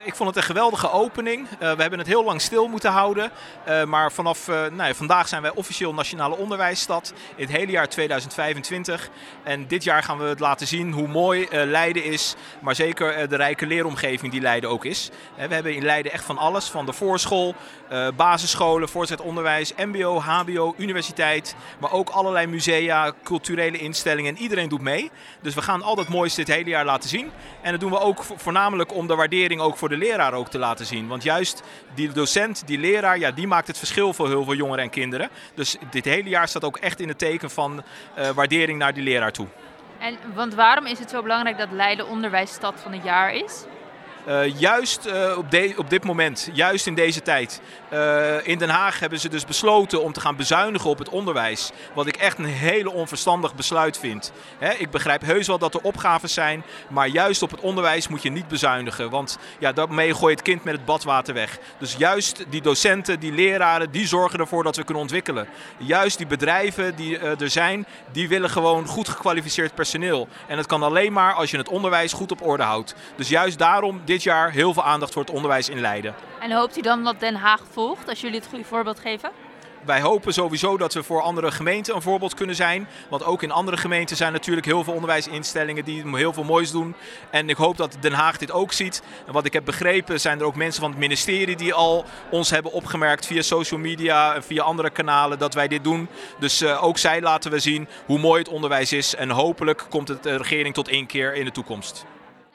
Wethouder Abdelhaq Jermoumi over de opening van het onderwijsjaar.